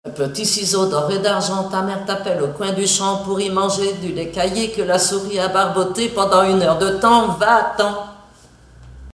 formulette enfantine : amusette
comptines et formulettes enfantines
Catégorie Pièce musicale inédite